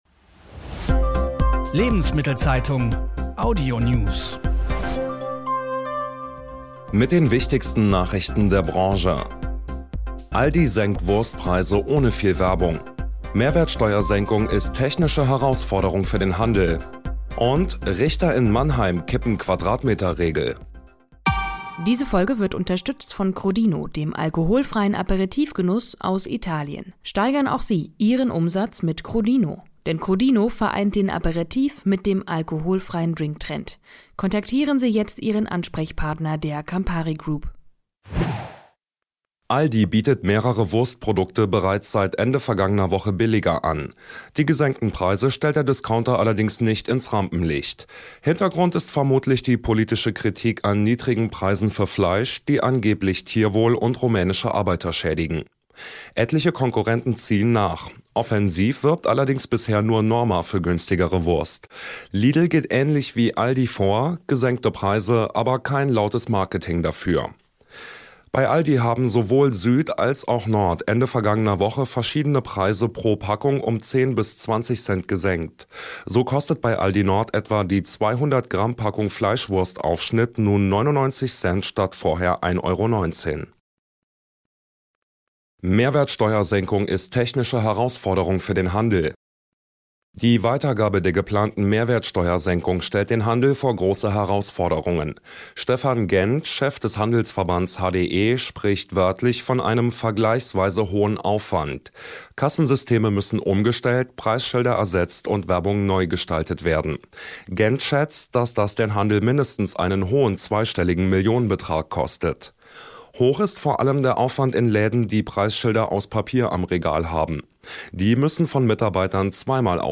Die wichtigsten Nachrichten aus Handel und Konsumgüterwirtschaft zum Hören